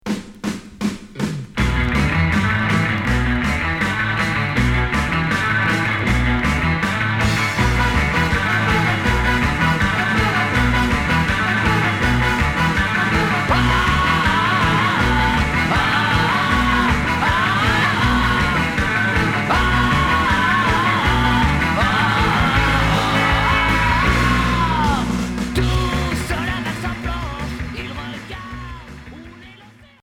Psychobilly punk